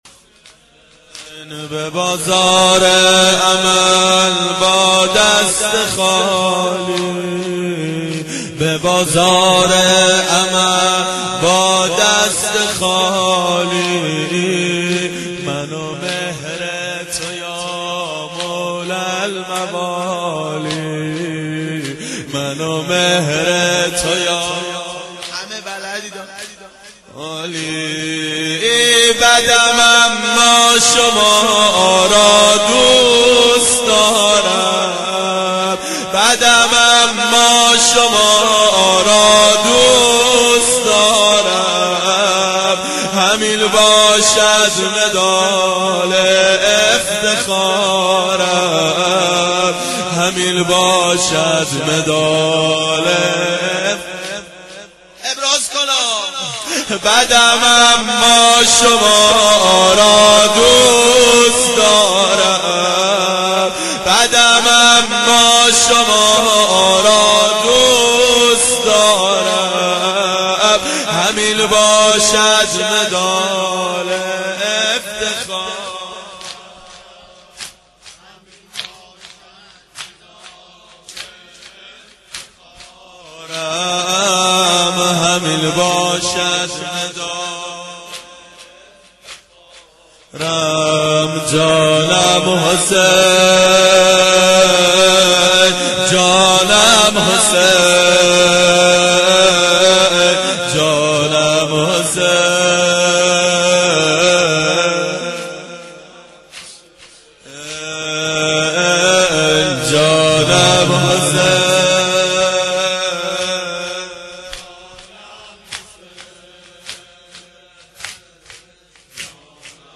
اربعین 86 هیئت متوسلین به امیرالمؤمنین حضرت علی علیه السلام